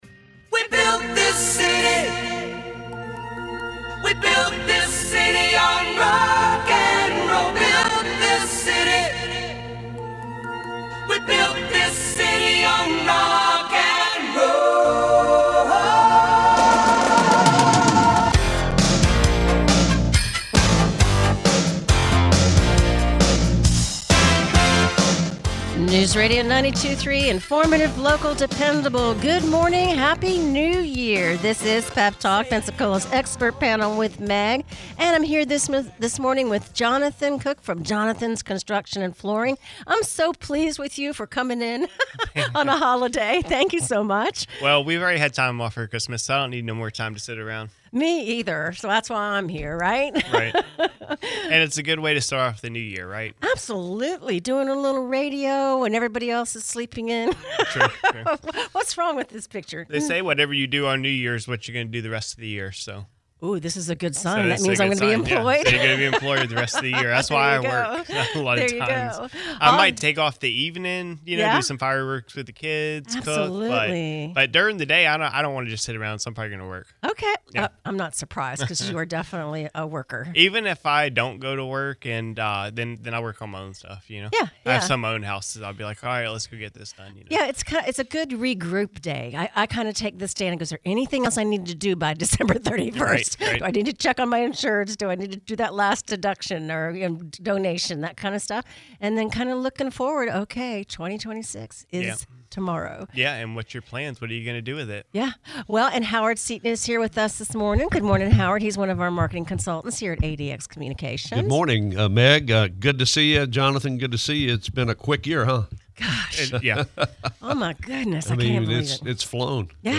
answer questions from listeners